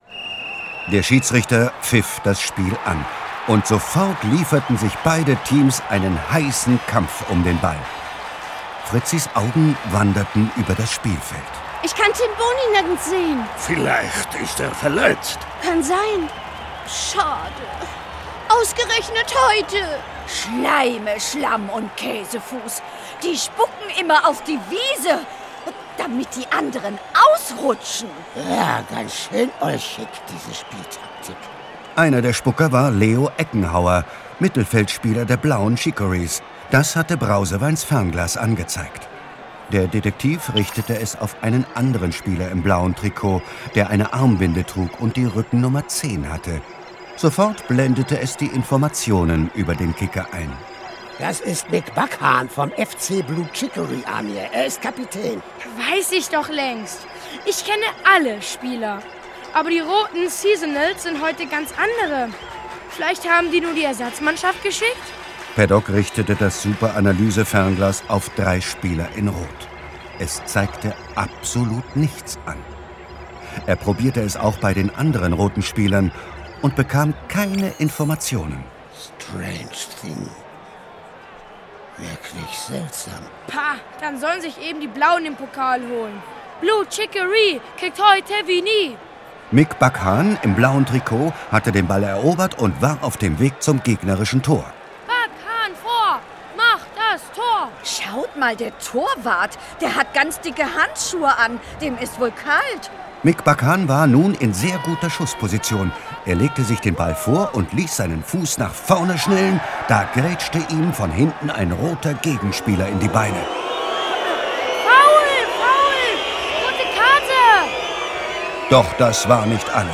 Ravensburger Olchi-Detektive 2 - Rote Karte für Halunken ✔ tiptoi® Hörbuch ab 6 Jahren ✔ Jetzt online herunterladen!